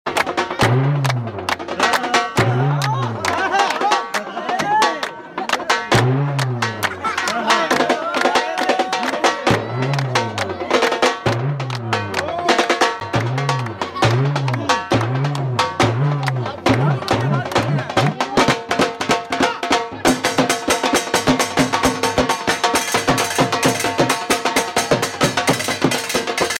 Dhol beat